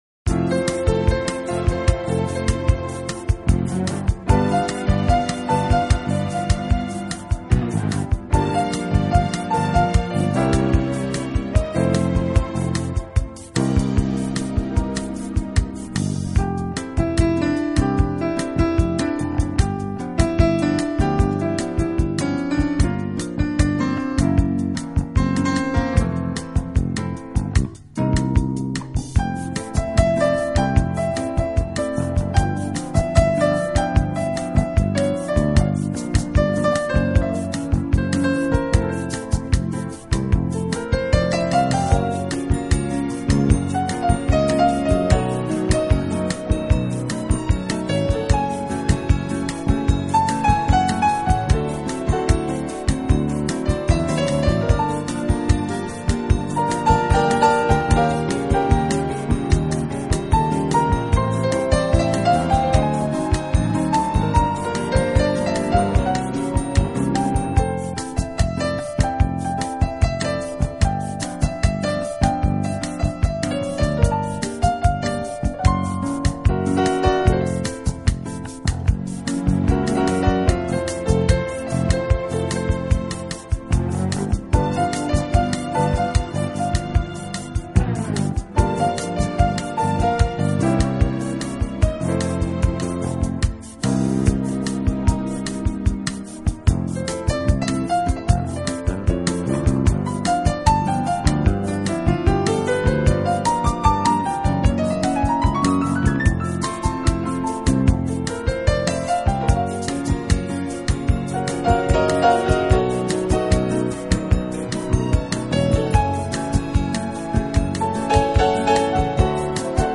【名品钢琴】